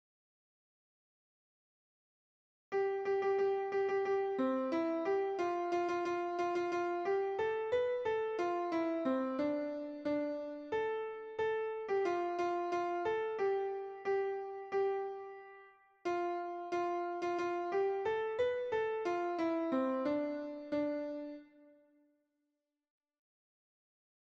Basse 1